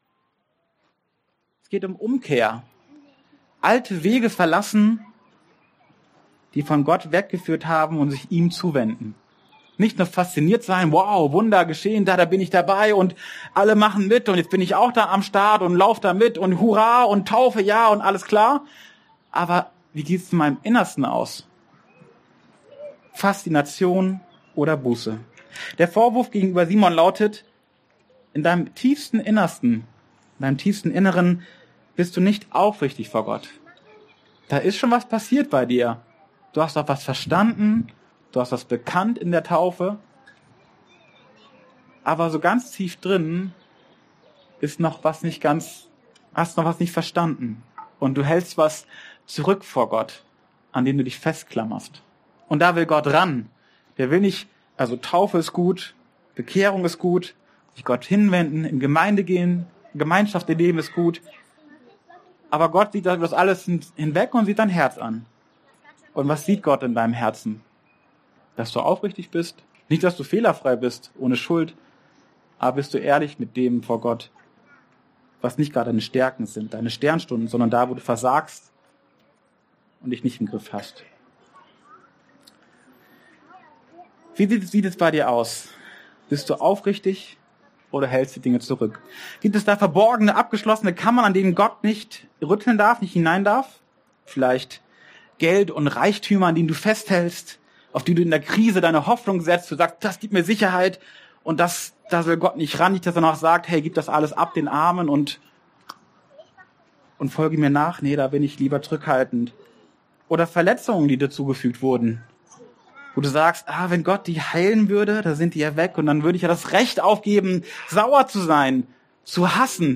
Der Anfang der Predigt wurde leider nicht aufgenommen und fehlt daher hier.
wie neu geboren Dienstart: Predigt Der Anfang der Predigt wurde leider nicht aufgenommen und fehlt daher hier.